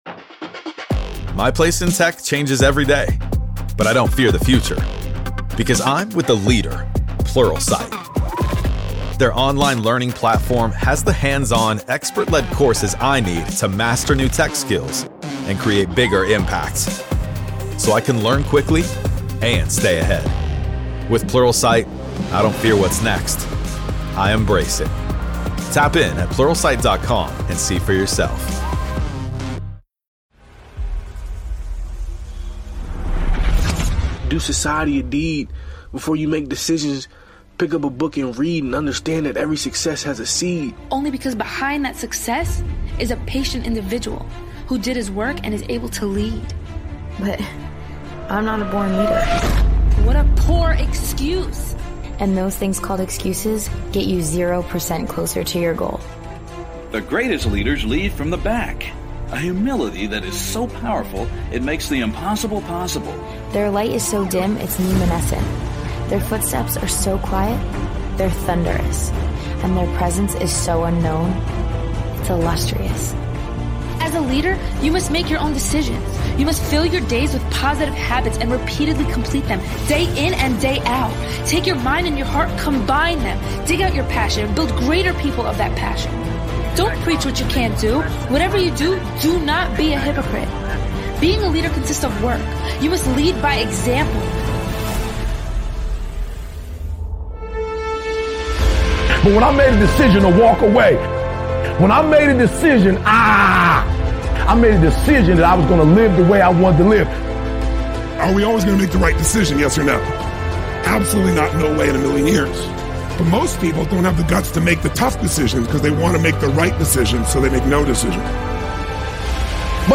Speakers: Jim Rohn